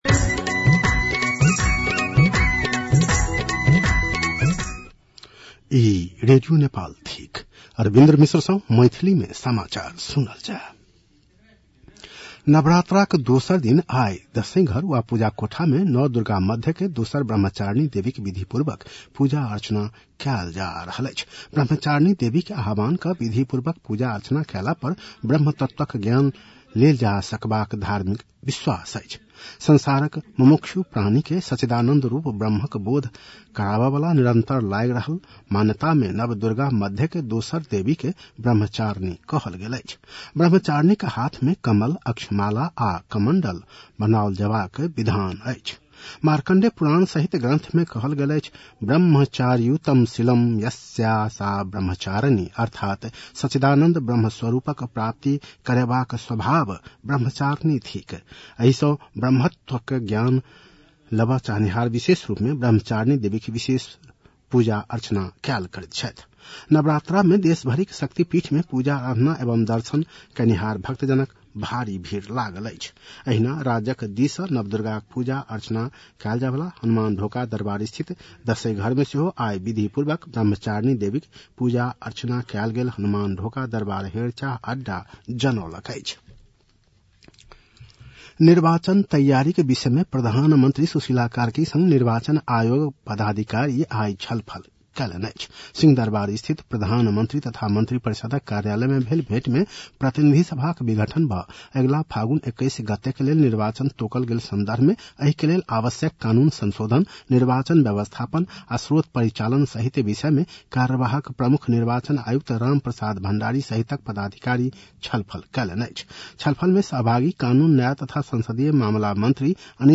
मैथिली भाषामा समाचार : ७ असोज , २०८२